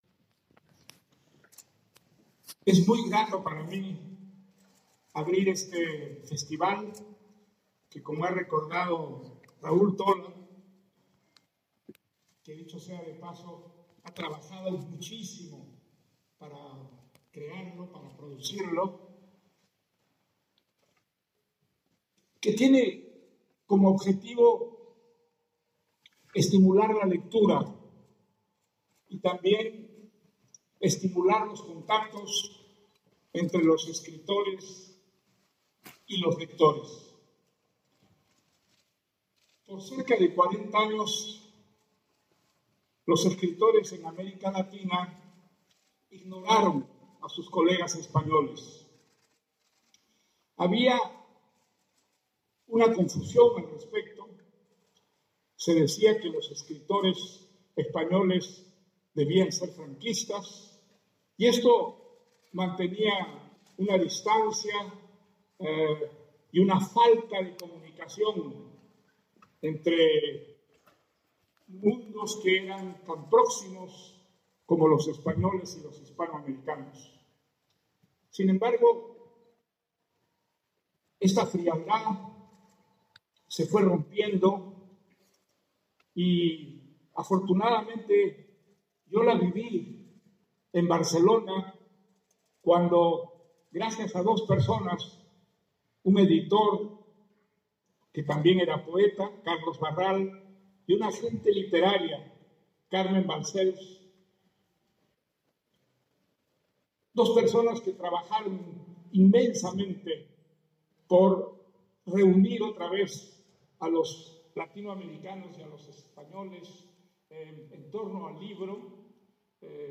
INAUGURACIÓN
El Premio Nobel Mario Vargas Llosa y Mircea Cartarescu han sido los encargados de protagonizar la primera charla en el auditorio para debatir sobre el papel de la literatura en un mundo nuevo.